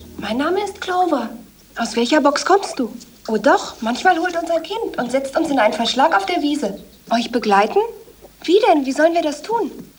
Für mich klingt die Sprecherin ihrem Alter entsprechend. Ich höre da keine Sprecherin, die älter wäre.